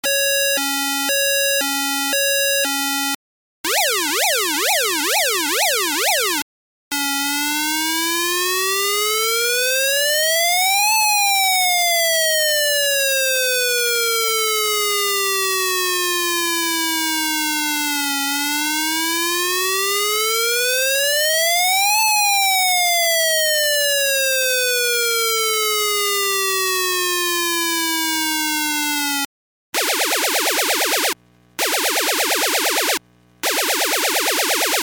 ４種類のサウンドを発生する基板です。
上記動作確認回路でパソコンに取り込んでＳｏｕｎｄ音を録音しました。
各ファイルはＥｍｅｒｇｅｎｃｙ　Ａｌａｒｍ （ピーポピーポ）　→　約１ｓ休止　→　Ａｌａｒｍ（ピヨピヨ）　→　約１ｓ休止　→Ｆｉｒｅ　Ａｌａｒｍ（ウ−ウ−）　→　約１ｓ休止　→Ｍａｃｈｉｎｅ　Ｇｕｎ （ダッダッダッ）の順番で録音しています。
３９０ｋΩ